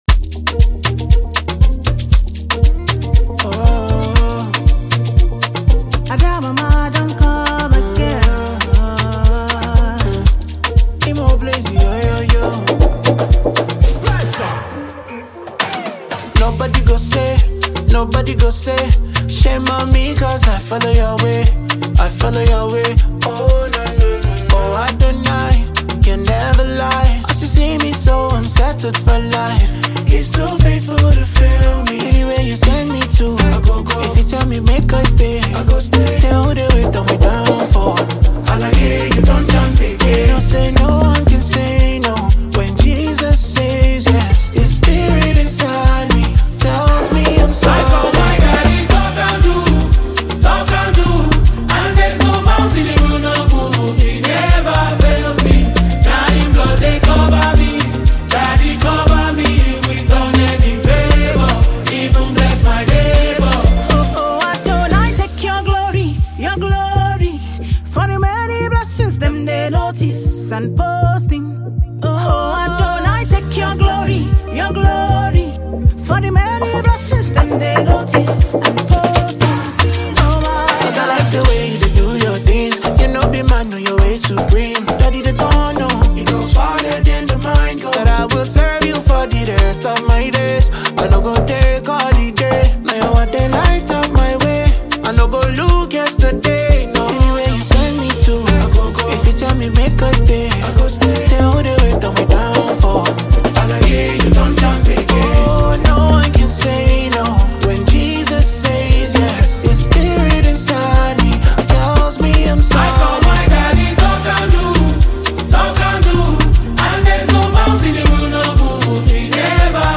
” an exuberant track about the power of walking in faith.
joyful afrobeat sound
comforting vocal